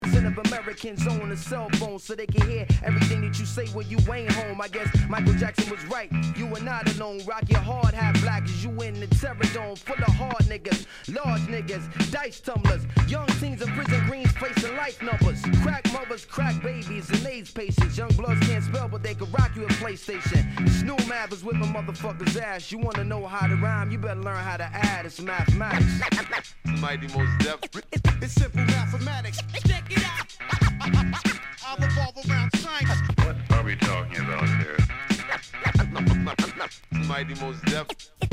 知的なラップを披露！サビのスクラッチがヤバッ！
Tag       HIP HOP HIP HOP